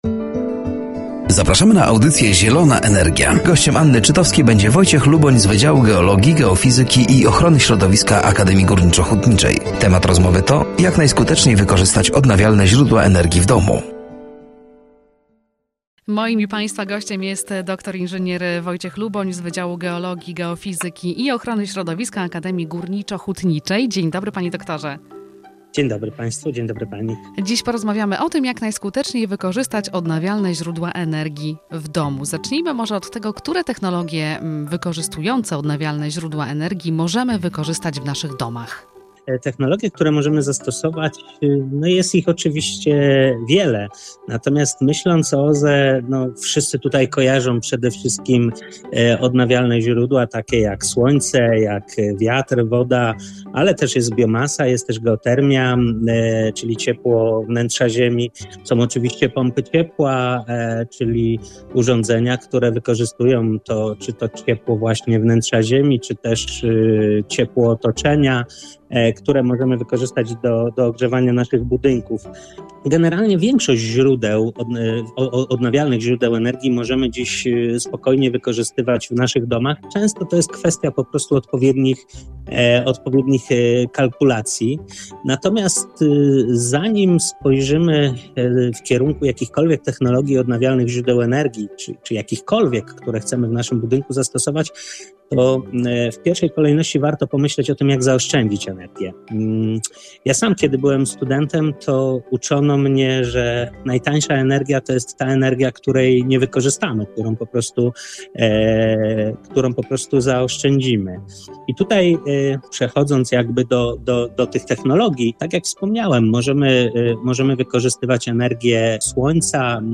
“Zielona Energia” w piątek o g. 10.15 na antenie Radia Nadzieja.